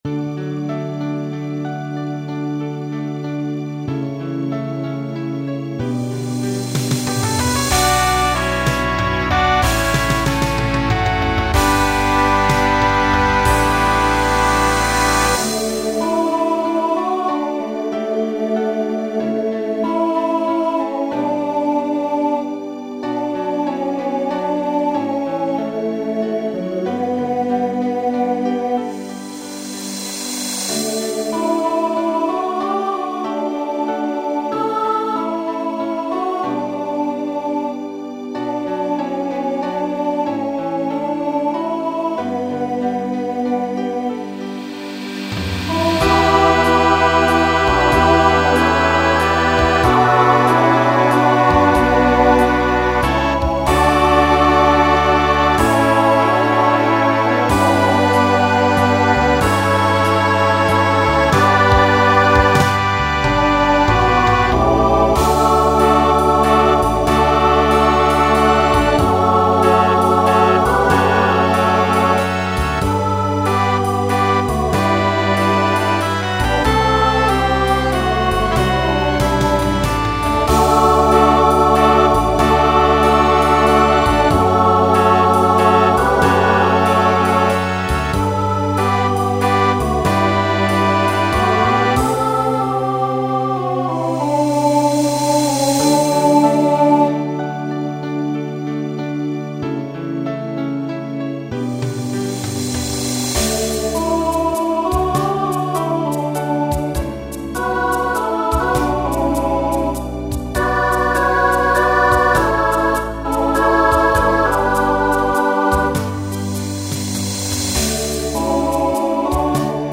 Genre Rock Instrumental combo
Ballad Voicing SATB